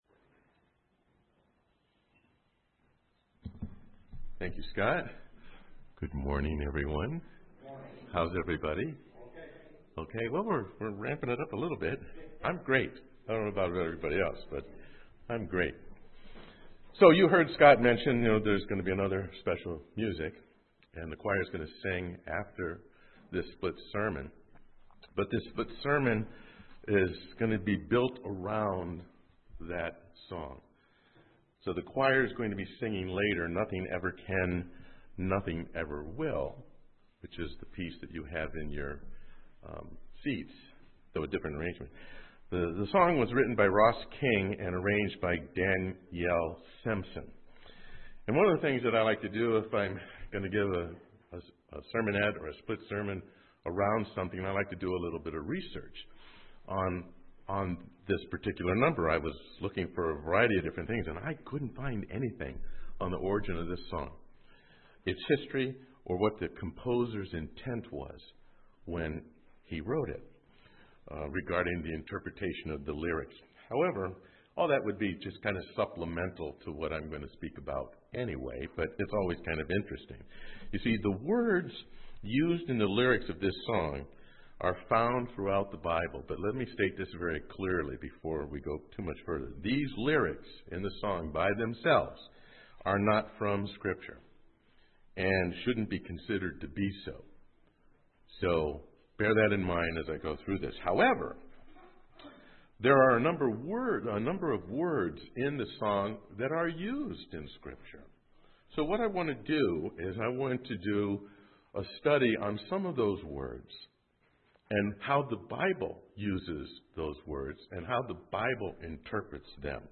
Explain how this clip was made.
Given in Ft. Wayne, IN